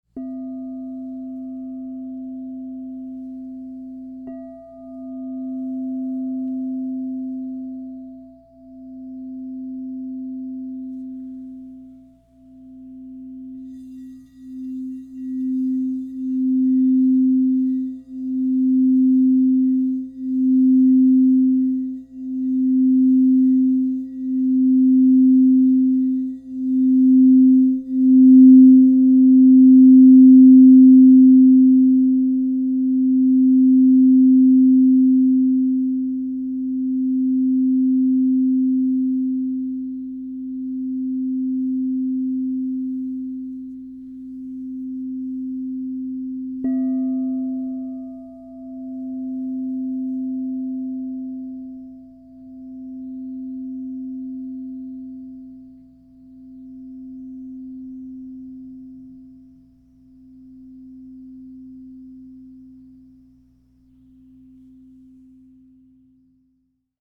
Genuine Crystal Tones® Alchemy Singing Bowl.TRUE TONE.
Androgynous Indium 10″ C -10 Crystal Tones® Singing Bowl
SKU: 155777 Out of stock Alchemy Androgynous Indium Brand Crystal Tones Cents -10 (True Tone) Color Purple Diameter 10" Frequency 432Hz (-), 440Hz (TrueTone) MPN 155777 Note C SKU: 155777